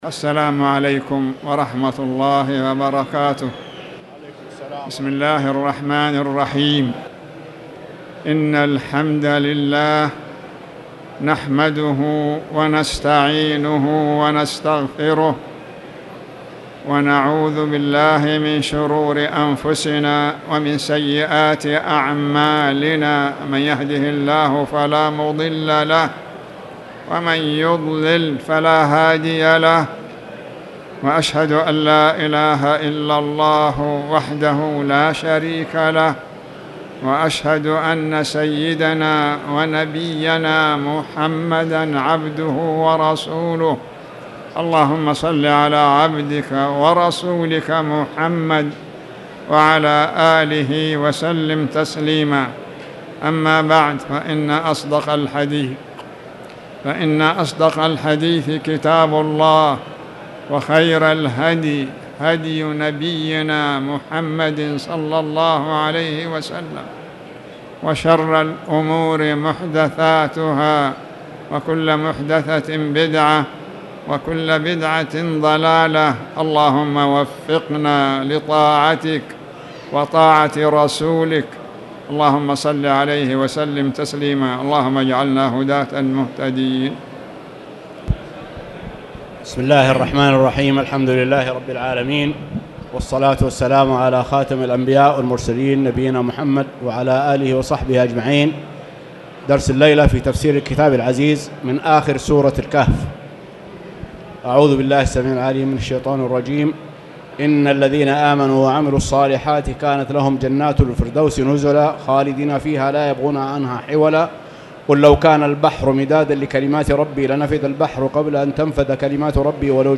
تاريخ النشر ١ ربيع الأول ١٤٣٨ هـ المكان: المسجد الحرام الشيخ